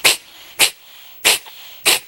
hairball.ogg